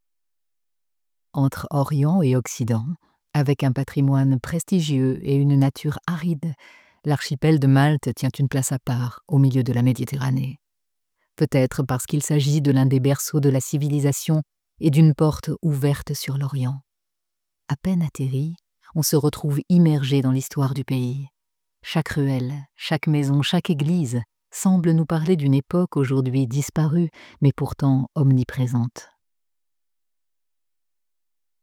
Publicité - Voix sensuelle
- Mezzo-soprano